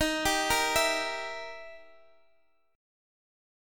Gm6/Eb chord